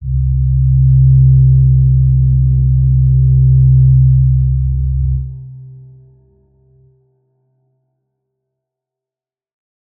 G_Crystal-C3-mf.wav